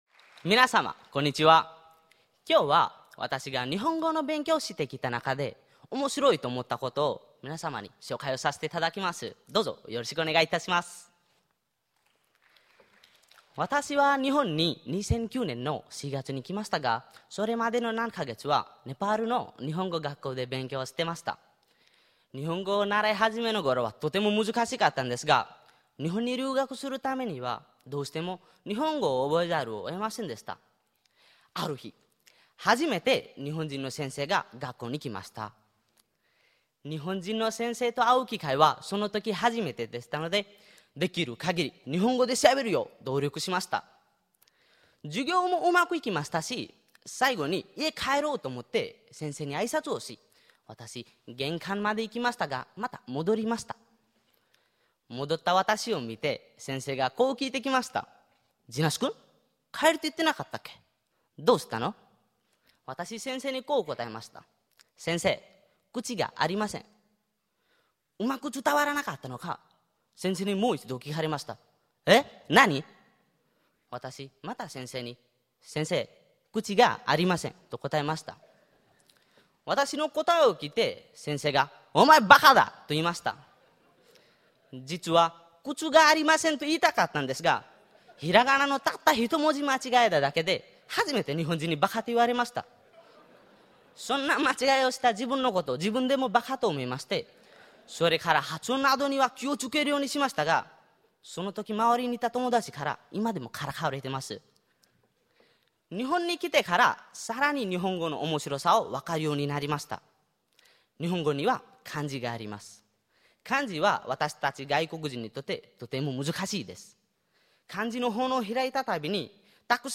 「外国人による日本語弁論大会」
第53回 2012年6月2日 別府国際コンベンションセンター「B-con Plaza」（大分県）